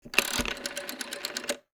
Rotary phone dialing sounds.
rotary-phone-1-nr7.wav